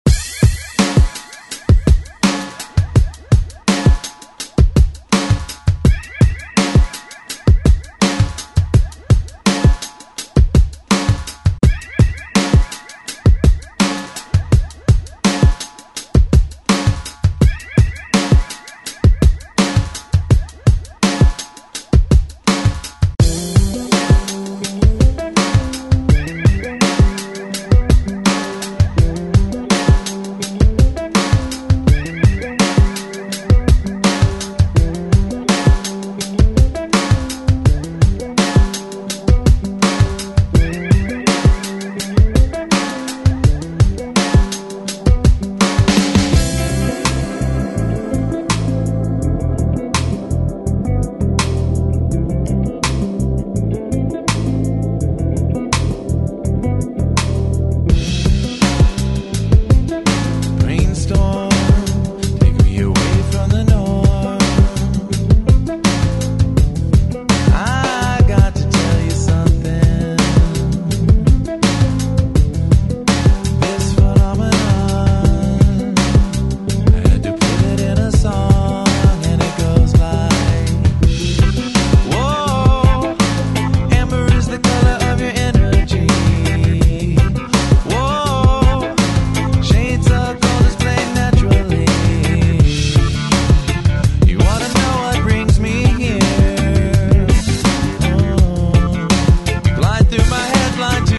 Genres: HIPHOP , RE-DRUM
Dirty BPM: 127 Time